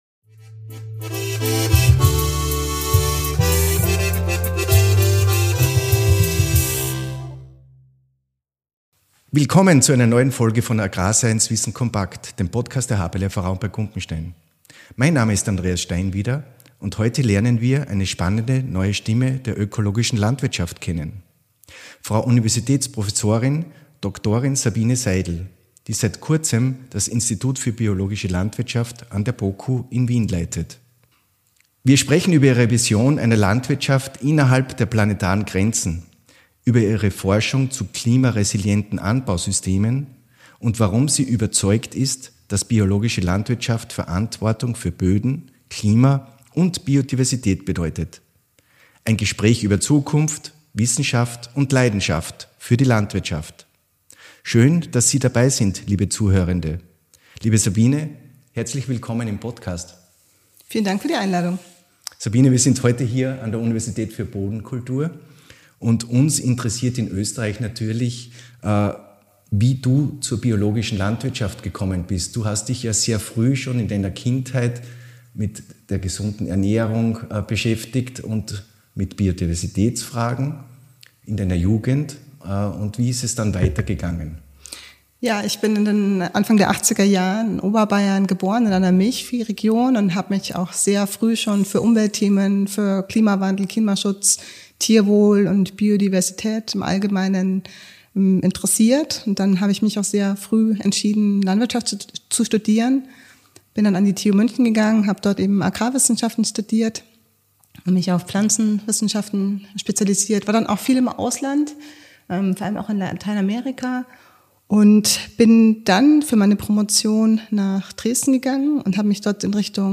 Wir erfahren, welche Rolle Mischkulturen, digitale Modelle und KI in der Forschung der Zukunft spielen, und wie Biolandwirtschaft helfen kann, innerhalb der planetaren Grenzen zu wirtschaften. Ein inspirierendes Gespräch über Wissenschaft, Nachhaltigkeit und die Zukunft des Bio-Landbaus.